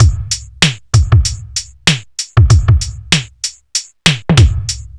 • 96 Bpm Breakbeat E Key.wav
Free breakbeat - kick tuned to the E note. Loudest frequency: 1698Hz
96-bpm-breakbeat-e-key-gap.wav